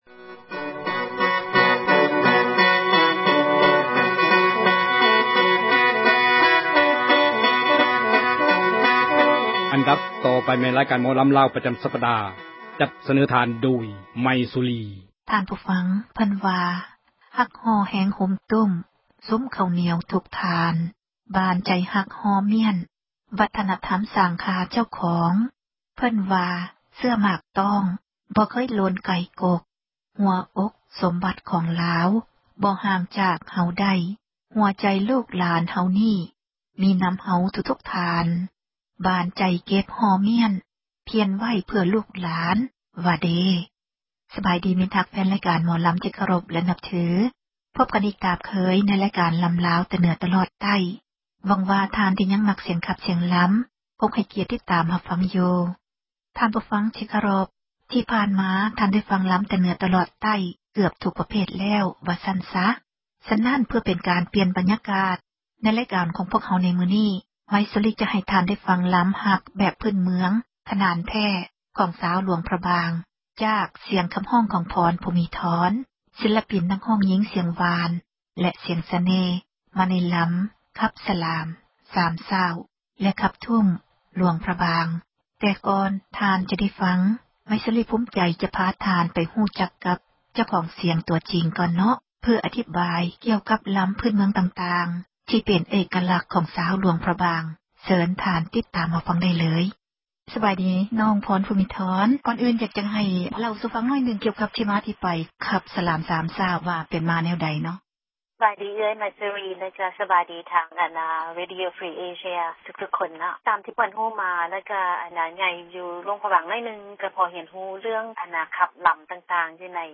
ເຊີນທ່ານ ຮັບຟັງ ຣາຍການ ໝໍລໍາ ແຕ່ເໜືອ ຕລອດໃຕ້ ປະຈໍາ ສັປດາ